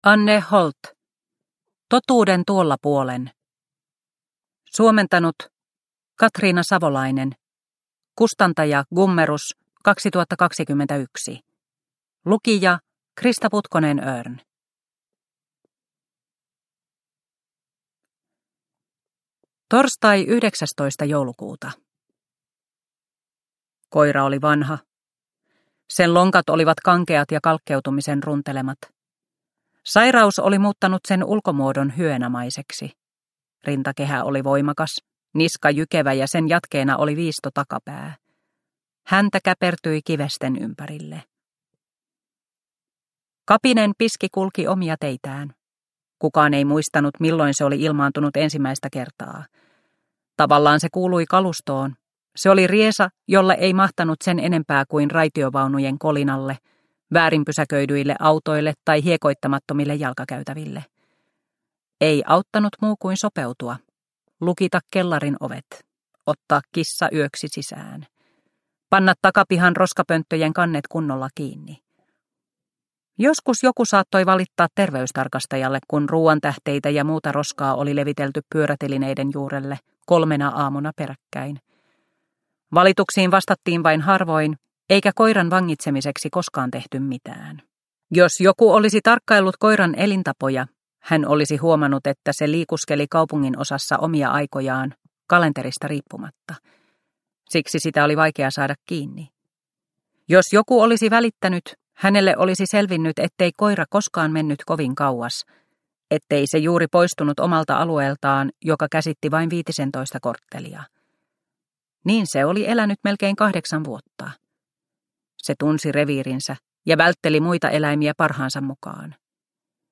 Totuuden tuolla puolen – Ljudbok – Laddas ner